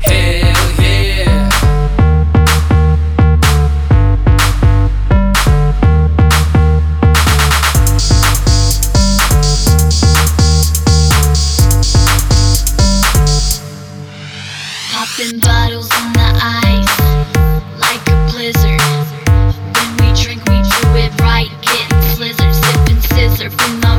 Duet Version Pop (2000s) 3:41 Buy £1.50